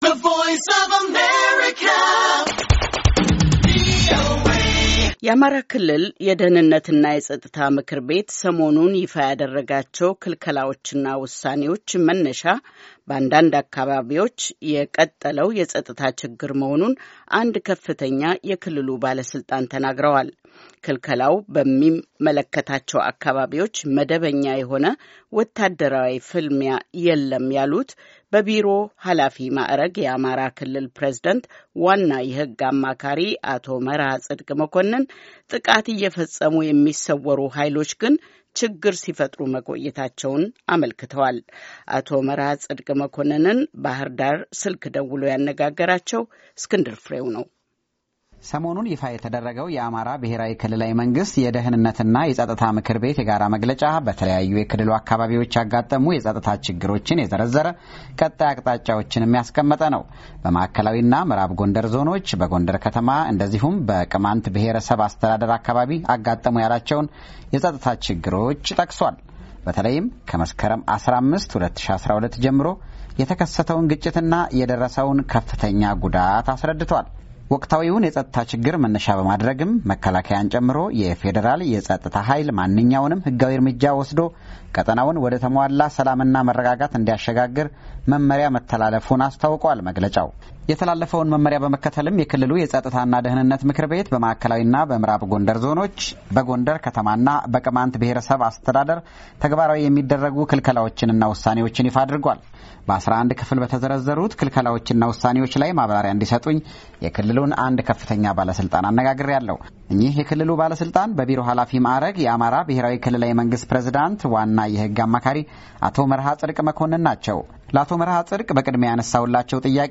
በቢሮ ኃላፊ ማዕረግ የአማራ ብሄራዊ ክልላዊ ፕሬዚዳንት ዋና የህግ አማካሪ የሆኑትን አቶ መርሃጽድቅ መኮነንን አነጋግረናቸዋል፡፡